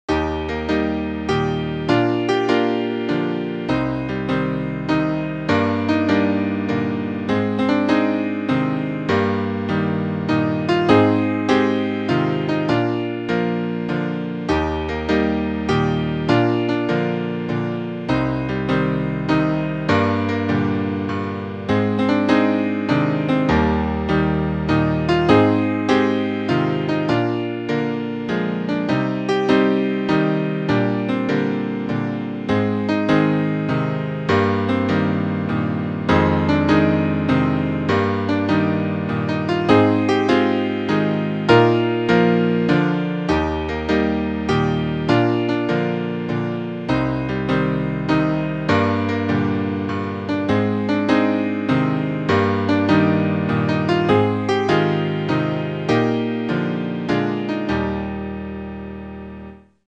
It was changed from quaver-arpeggios to a staggered bass – a bass-note played on the first beat of every bar, with one or two notes played on each subsequent beat.
Imsawn Bycbeys-sa is in the key of Eb major, the same as Forever We Stand. It consists of four 8-bar sections, each divided into four 2-bar phrases.
The chorus (3rd section) uses different chords, with a more minor tonality: Cm | Gm/Bb | Ab | Eb/G | Fm7 | Eb/G | Ab | Bb(sus4).